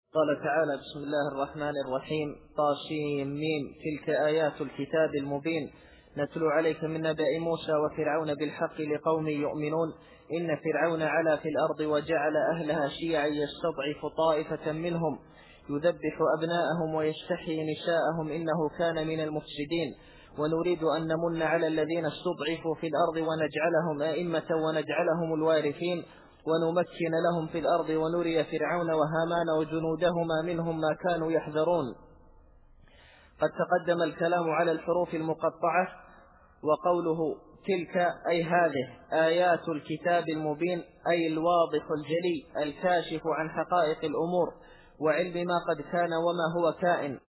التفسير الصوتي [القصص / 2]